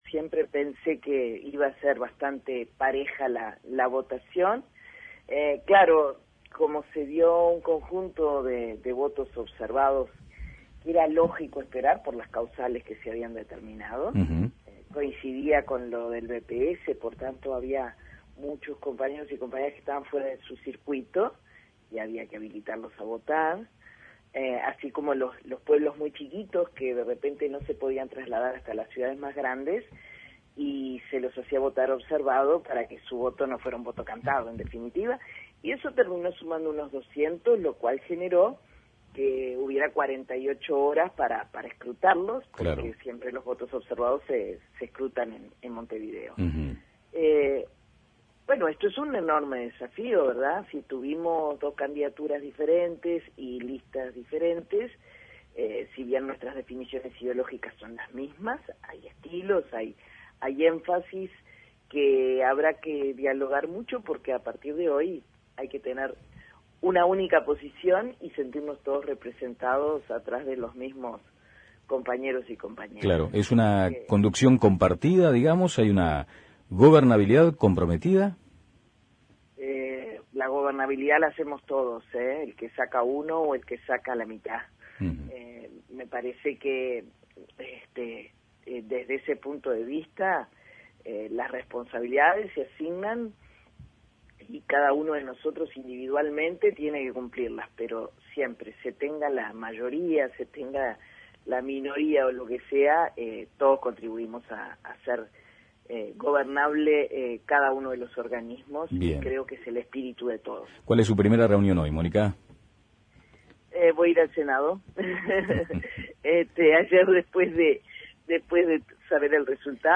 En entrevista en La Mañana de El Espectador, Xavier resumió cuáles serán algunas de sus prioridades en la conducción de esa fuerza política: Xavier en La Mañana de El Espectador Descargar Audio no soportado